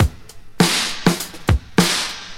Tuned drums (D# key) Free sound effects and audio clips
• 101 Bpm Drum Loop D# Key.wav
Free drum groove - kick tuned to the D# note.
101-bpm-drum-loop-d-sharp-key-XoP.wav